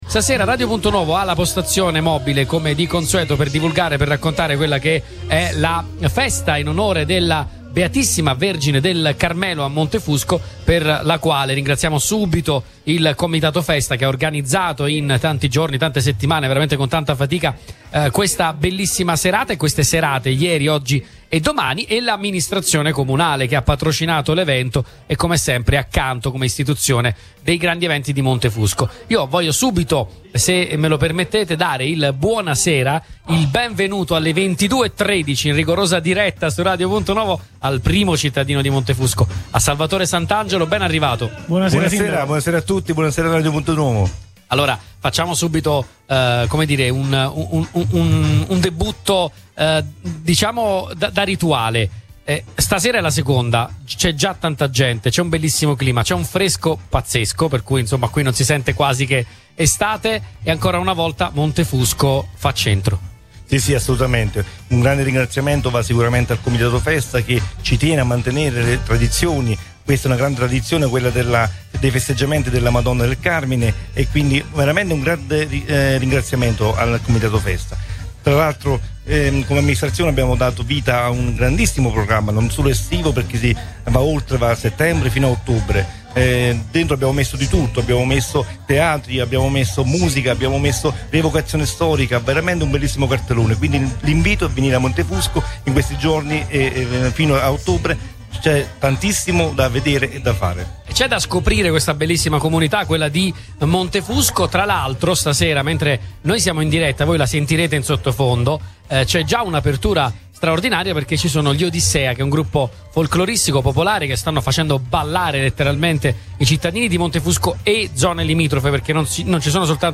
Lo ha detto con chiarezza e passione anche il sindaco Salvatore Santangelo, ospite ai microfoni di Radio Punto Nuovo nella serata del 2 agosto, trasmessa in diretta dalla nostra postazione mobile